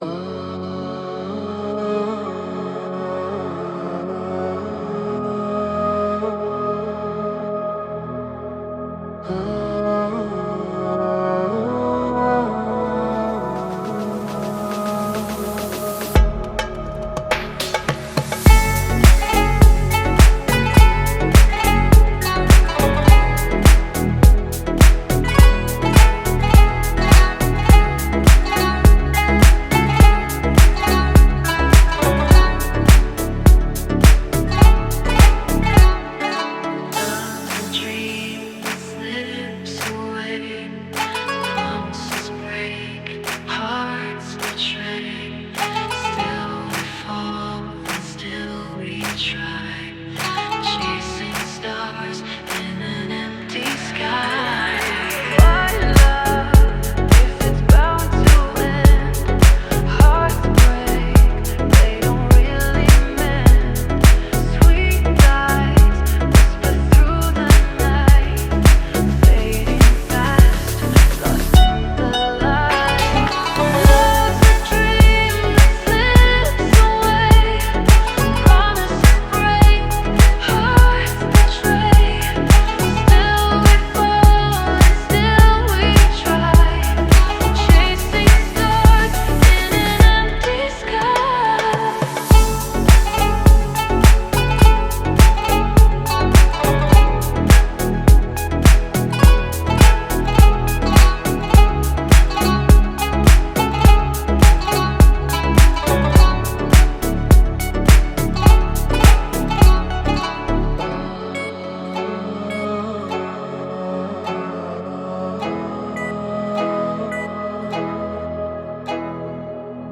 Deep House 2025